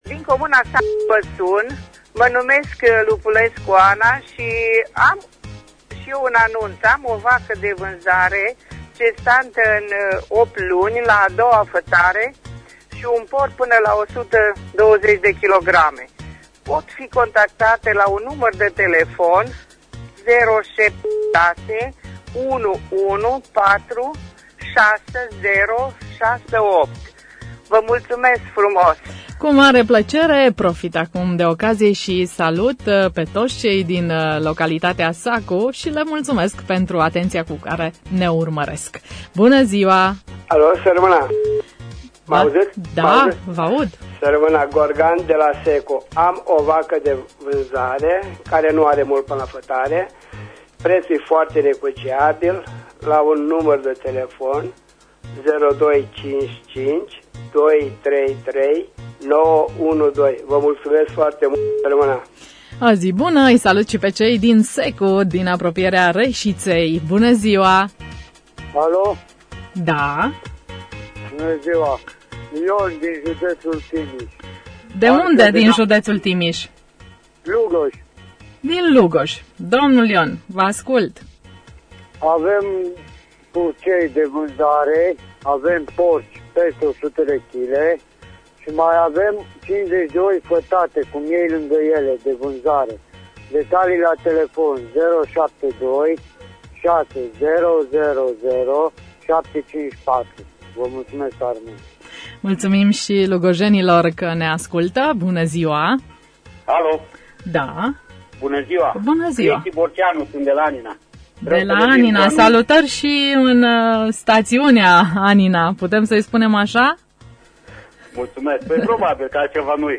Înregistrarea emisiunii „Târgul de bunuri” de miercuri, 27.01.2016, difuzată la Radio România Reşiţa.